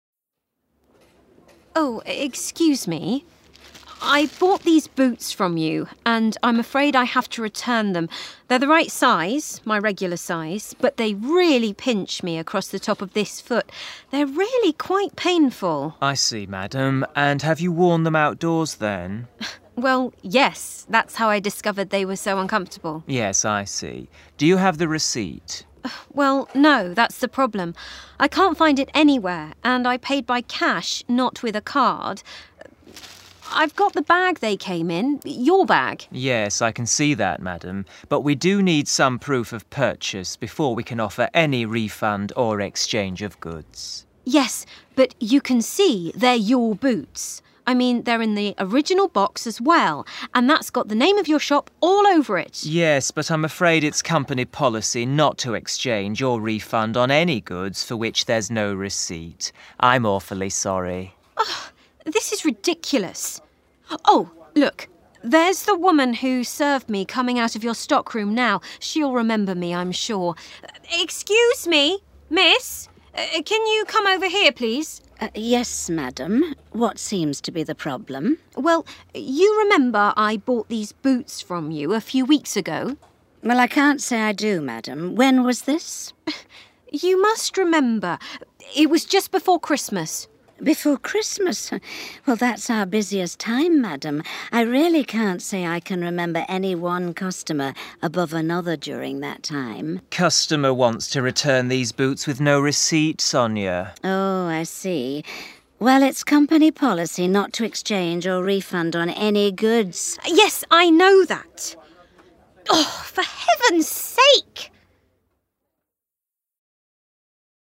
This activity provides short listening practice based around a conversation between a customer and sales person. The conversation is to return a pair of boots.
audio-bank-situations-returning-goods-to-shop.mp3